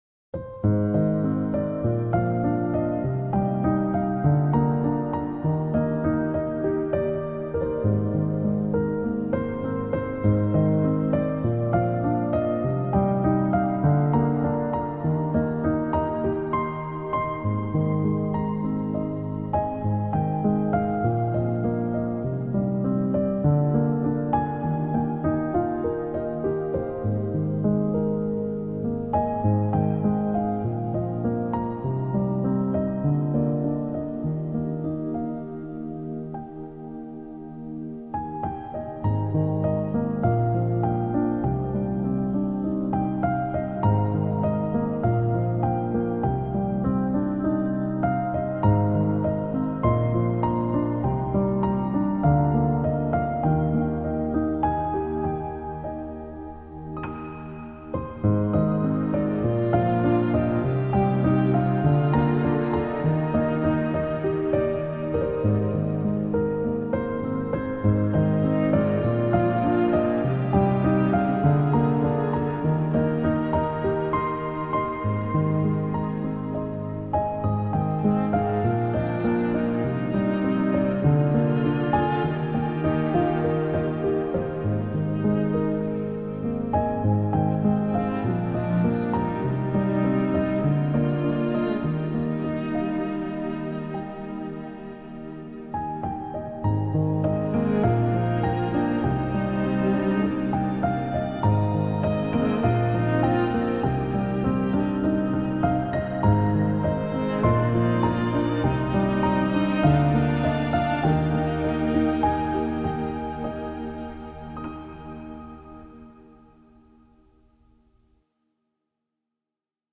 آرامش بخش
نیو ایج